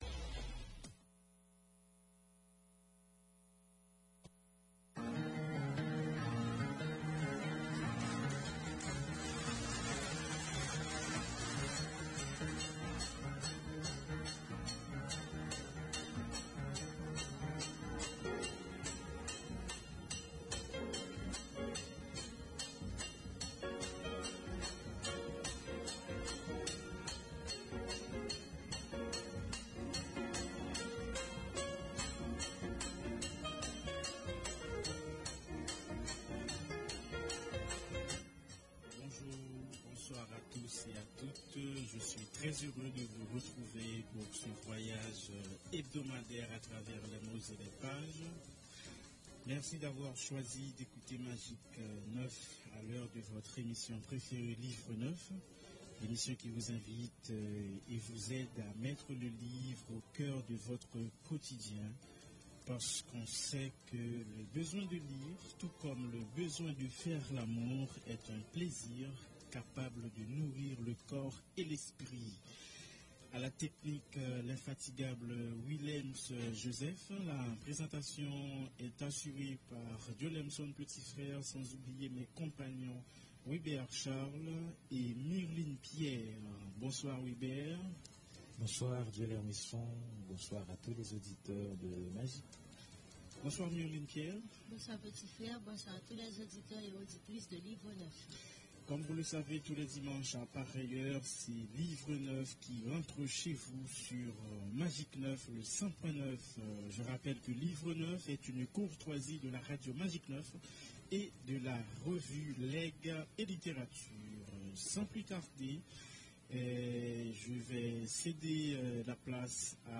Figures de femme dans l’art haïtien Invitée